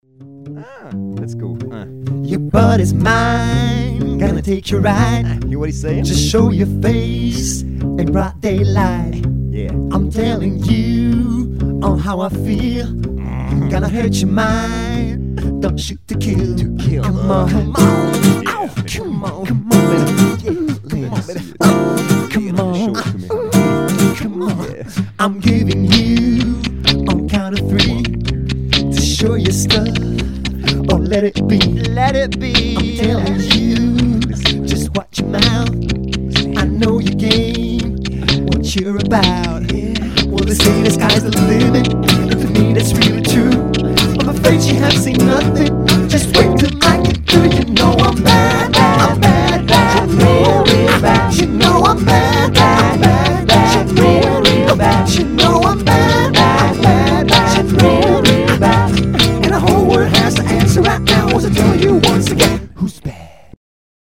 pas live (nov 2004), avec un Rhodes